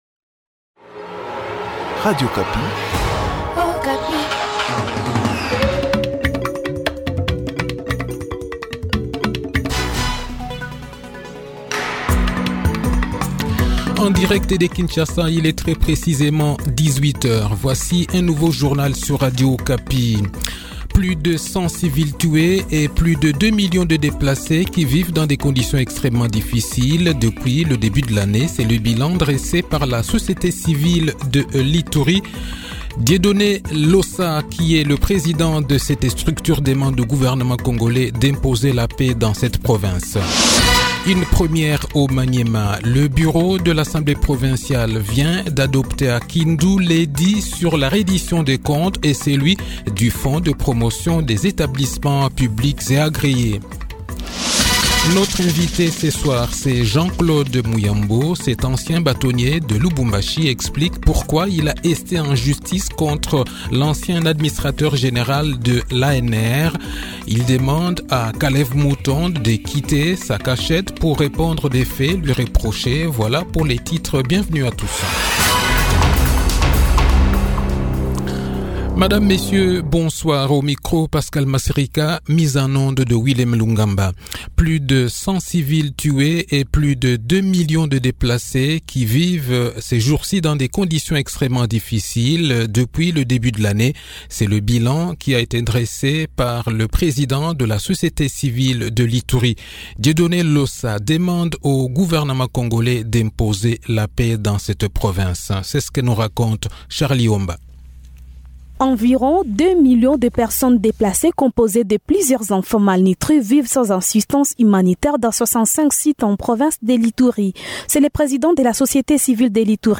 Le journal de 18 h, 10 Avril 2021
• Lubumbashi-Invité :  Jean-Claude Muyambo,Ancien Batonnier